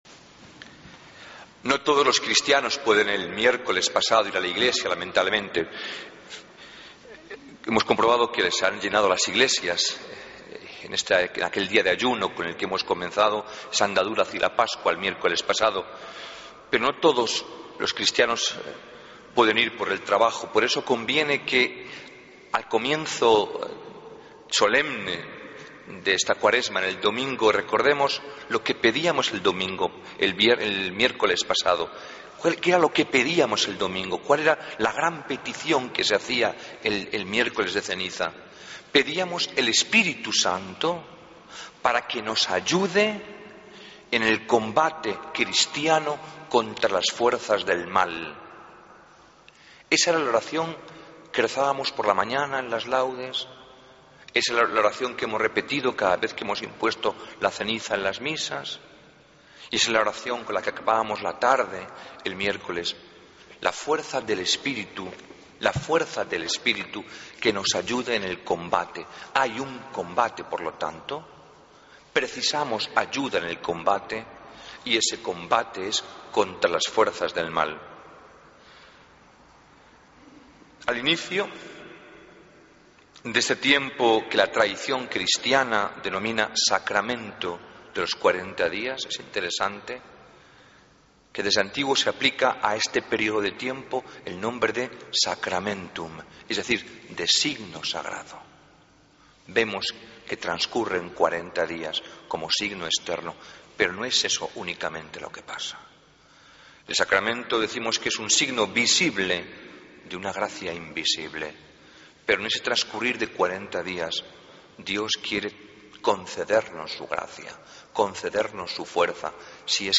Homilía del Domingo 9 de marzo de 2014